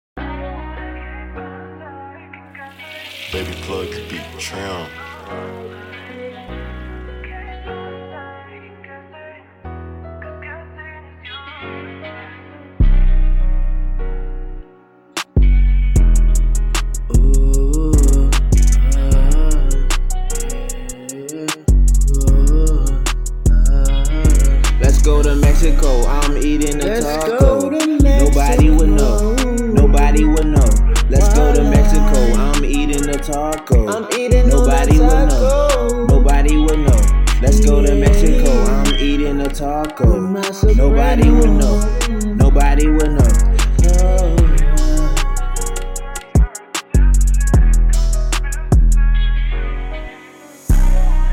Rap
Mexican R&B type joint